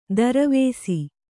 ♪ daravēsi